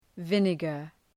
Προφορά
{‘vınəgər}